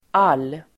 Uttal: [al:]